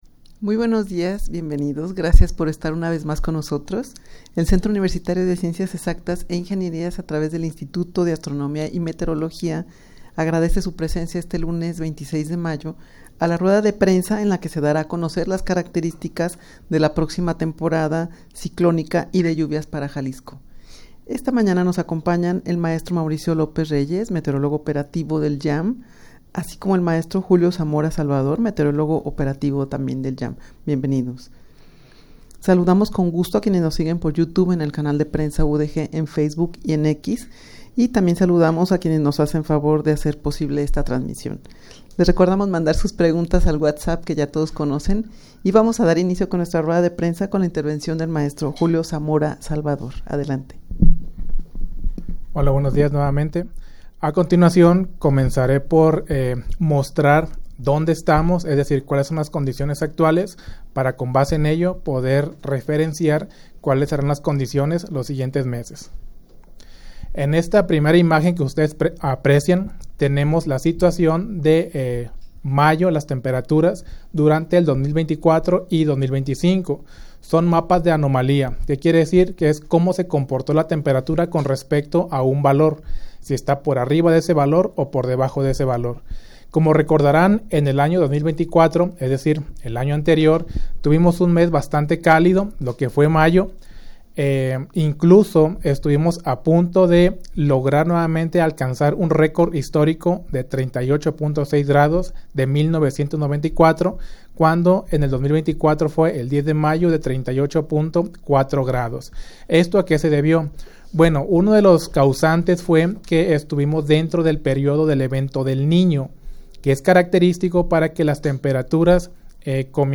Audio de la Rued de Prensa